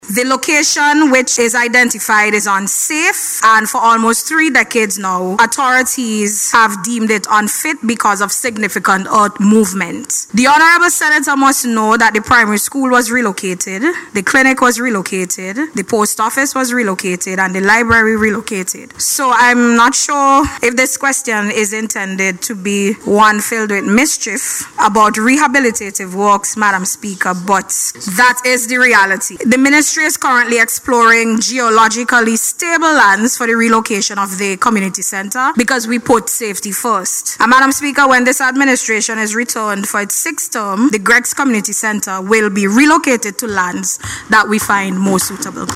Speaking in Parliament yesterday, Minister of National Mobilization Keisal Peters announced that the Ministry is actively identifying more geologically stable land, reaffirming the government’s commitment to community safety and development.